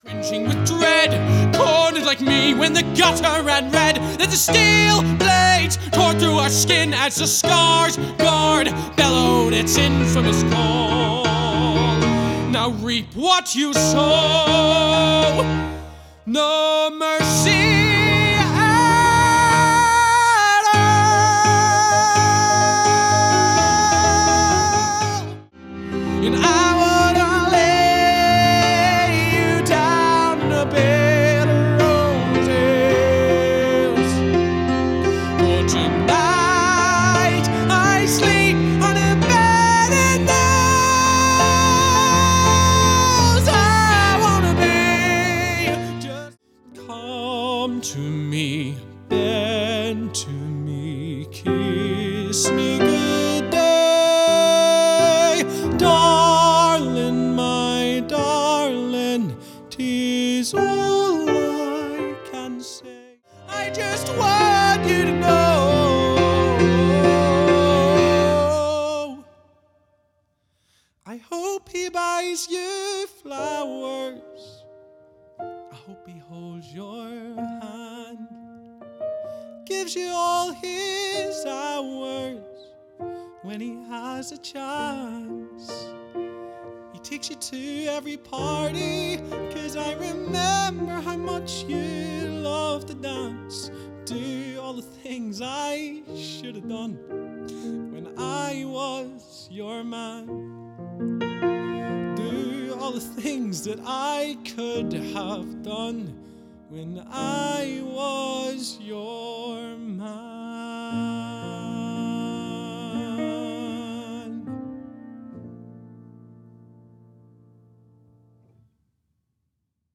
Singing Showreel
Male
Irish